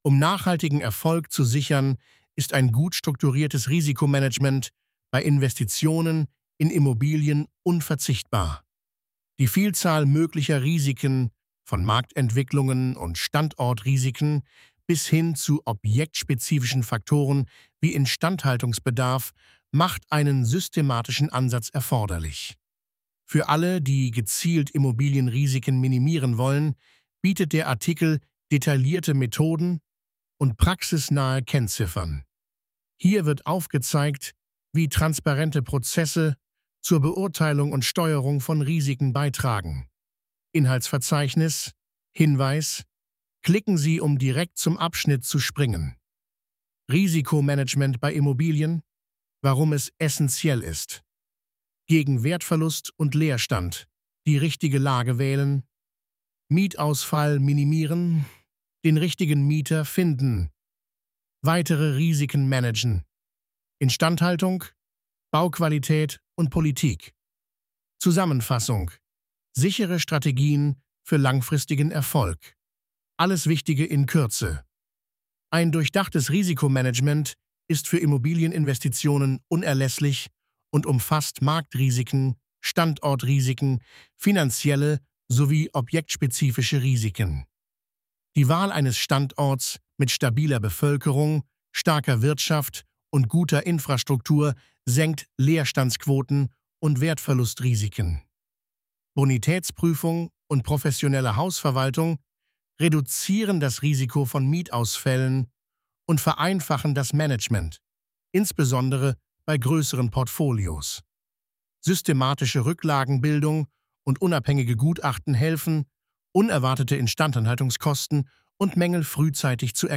Lassen Sie sich den Artikel von mir vorlesen.